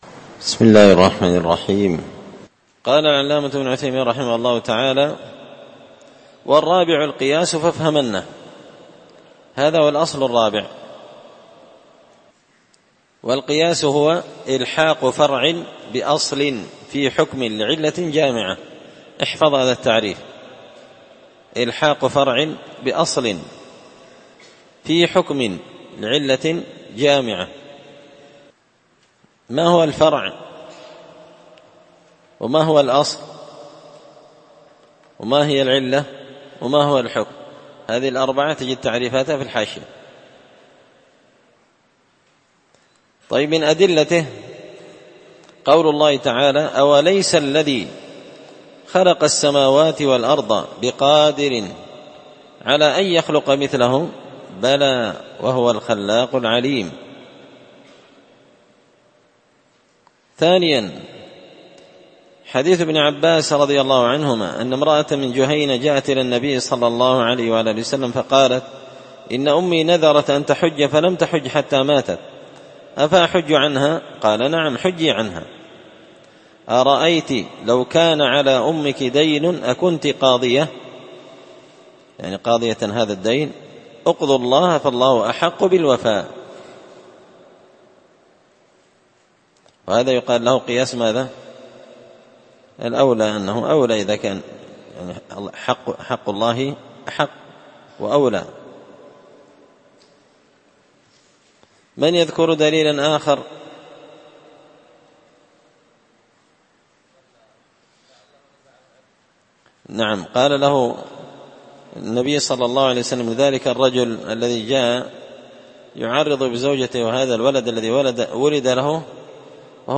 تسهيل الوصول إلى فهم منظومة القواعد والأصول ـ الدرس 28
مسجد الفرقان